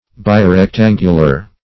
Search Result for " birectangular" : The Collaborative International Dictionary of English v.0.48: Birectangular \Bi`rec*tan"gu*lar\, a. [Pref. bi- + rectangular.] Containing or having two right angles; as, a birectangular spherical triangle.